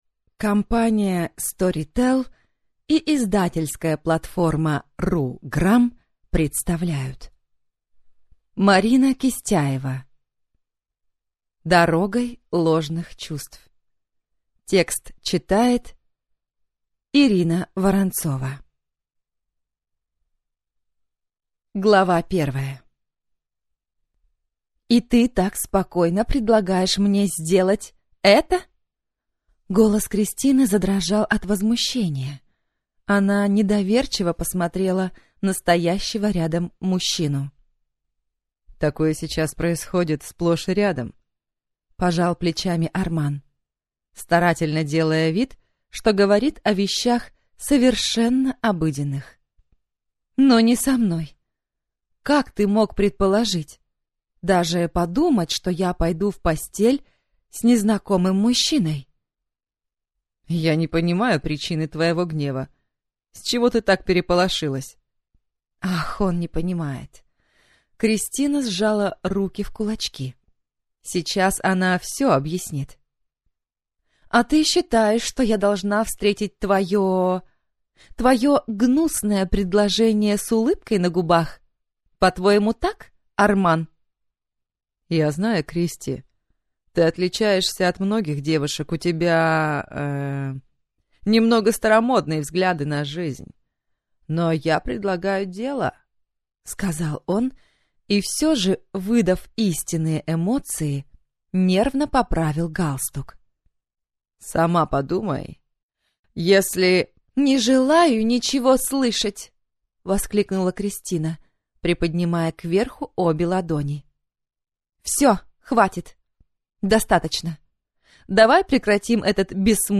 Аудиокнига Дорогой ложных чувств | Библиотека аудиокниг